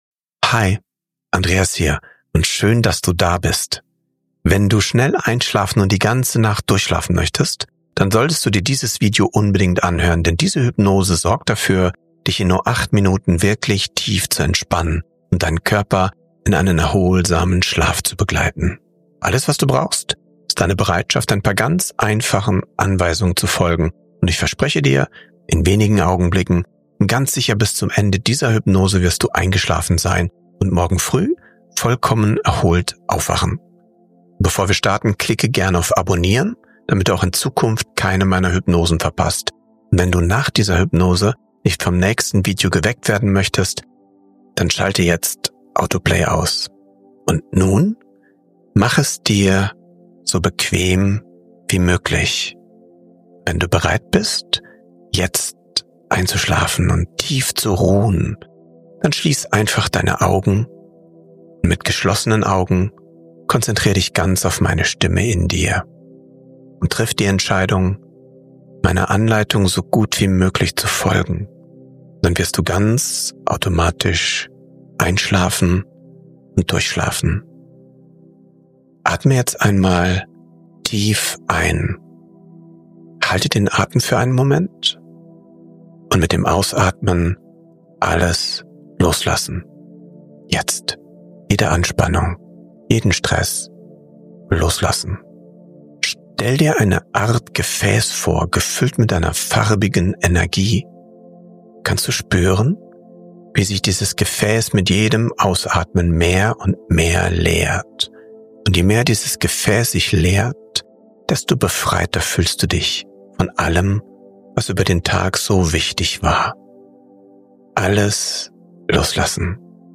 Geführte Hypnose für alle, die einfach nur schlafen wollen – schnell & zuverlässig
hypnotischer Sprache, ruhiger Musik und klarer